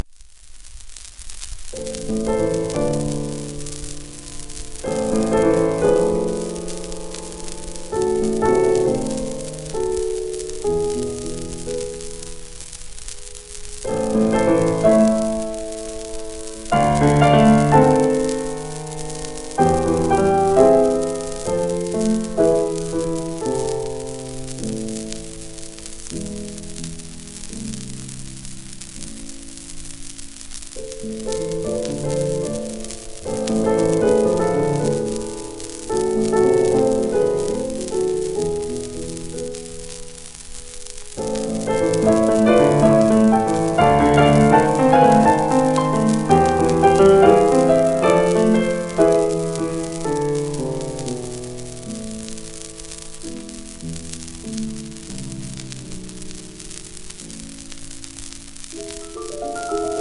1947年録音
シェルマン アートワークスのSPレコード